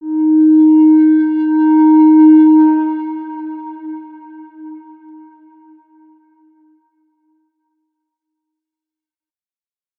X_Windwistle-D#3-pp.wav